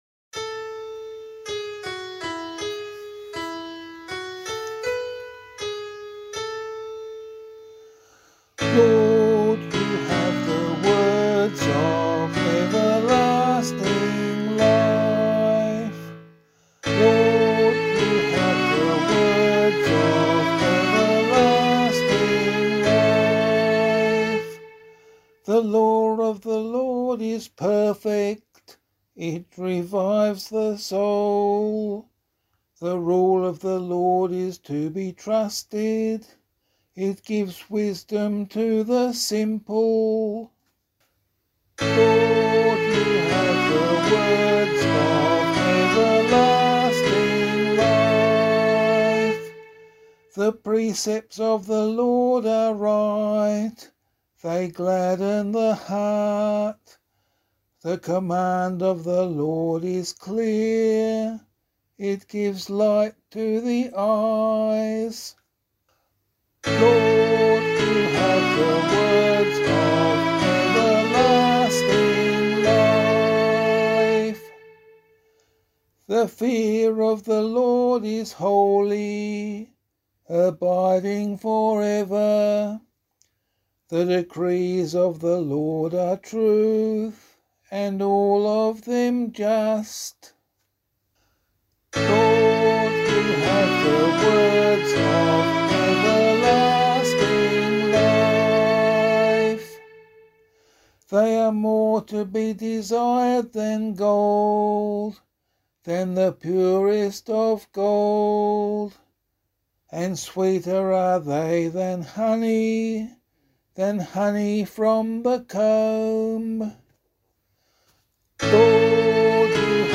015 Lent 3 Psalm B [LiturgyShare 8 - Oz] - vocal.mp3